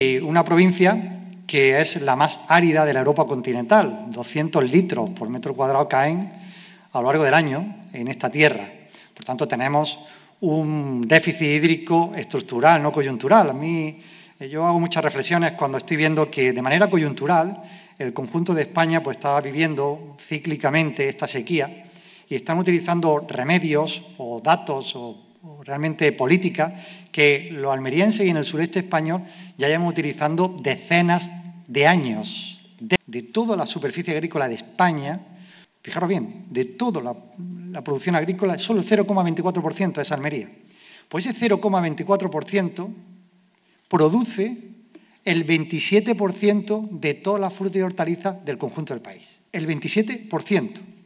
El Espacio Escénico de Pulpí ha acogido este foro de debate en el que destacados expertos han analizado los desafíos del uso del agua para el desarrollo económico de la provincia
El presidente de la Diputación de Almería, Javier A. García, ha dado la bienvenida a público y participantes de este encuentro que ha contado con dos mesas redondas: ‘Agua y agricultura sostenible’ y ‘Agua como elemento esencial para el desarrollo económico’.
16-09_foro_agua_presidente_javier_garcia.mp3